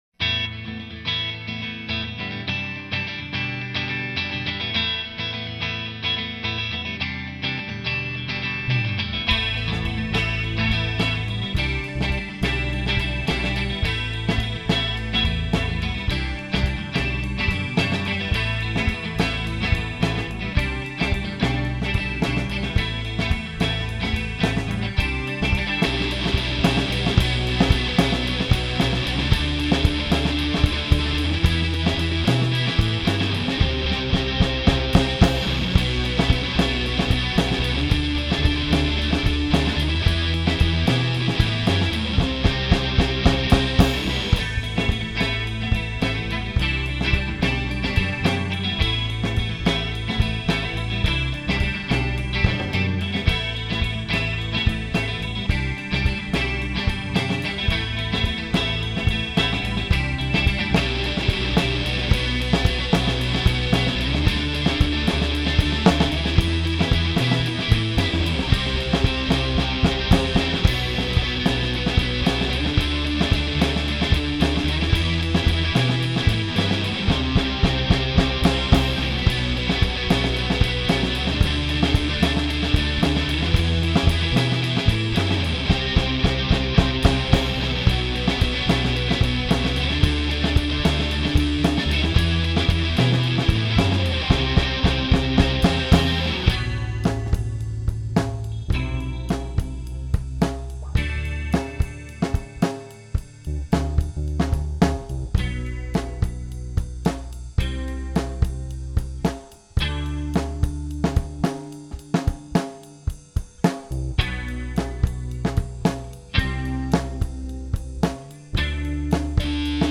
Recorded it on a Tascam 244 in my parents garage on a weekend in September 2003, it was the first time I'd play all of the instruments and sing on a recording.